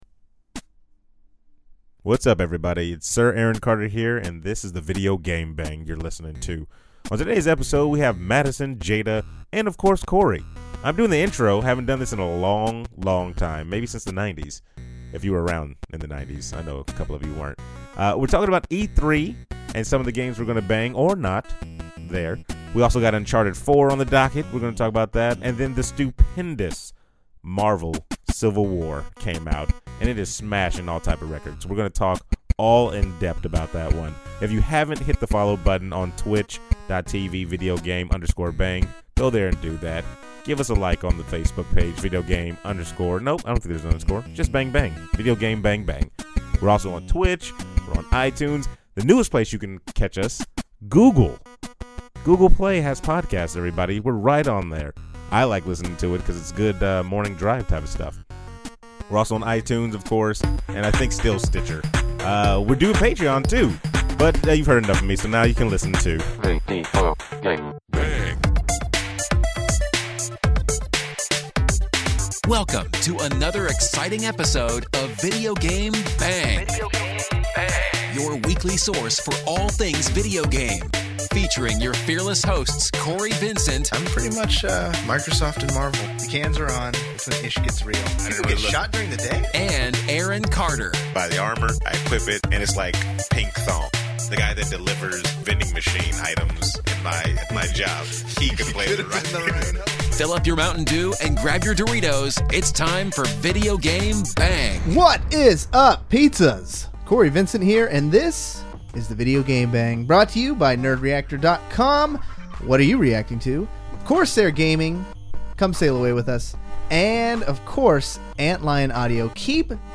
This week we are equal part male and female